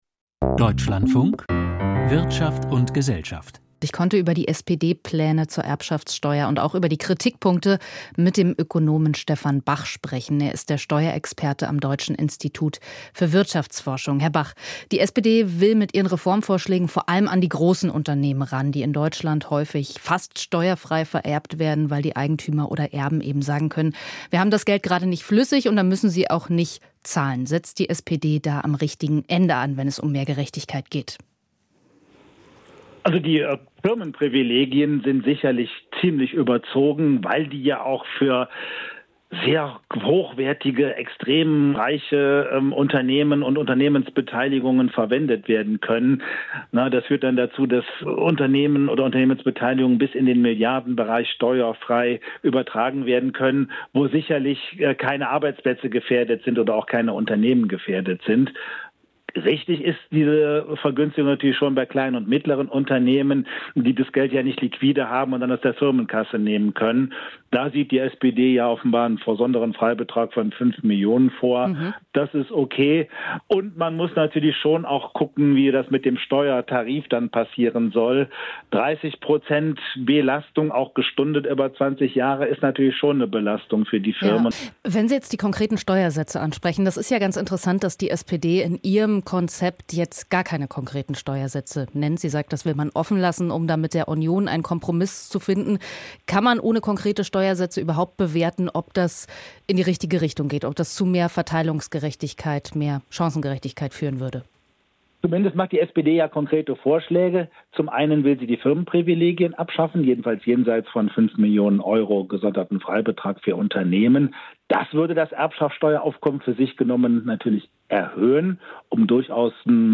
Erbschaftssteuer: Mehr Gerechtigkeit oder wirtschaftsfeindlich? Int.